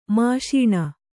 ♪ māṣīṇa